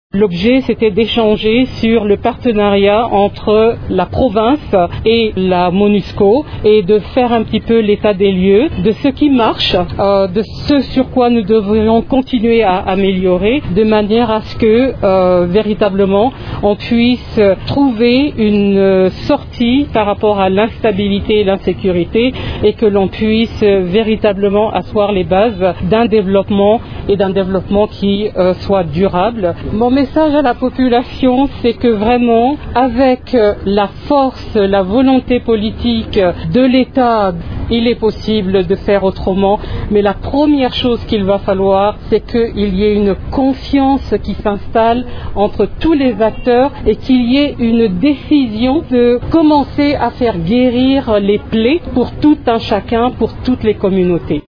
La chef de la MONUSCO a fait cette déclaration à l’issue de sa rencontre avec les membres du comité provincial de sécurité :